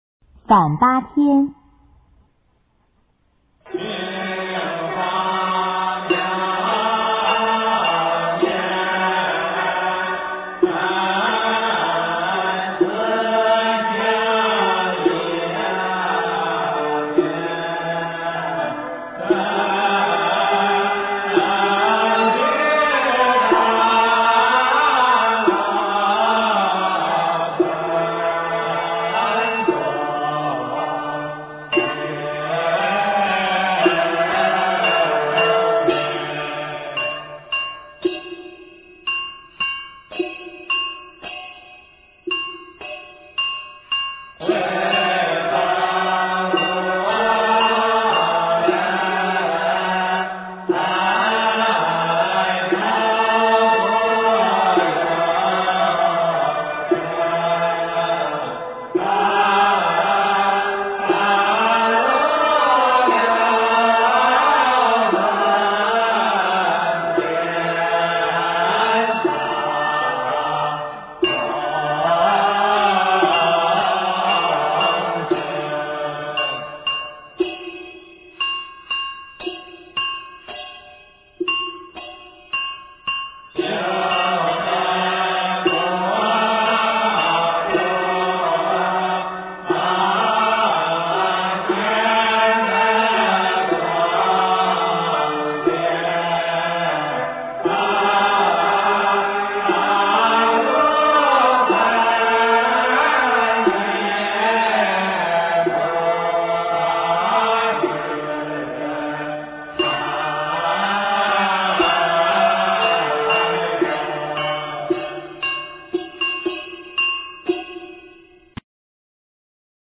中国道教音乐 全真正韵 反八天
第一、第三两段与早坛《提纲》相同，仅移低八度演唱，B角调，但中段旋律全然不同，用徵调，中段曲名仍用《反八天》、《玄蕴咒》。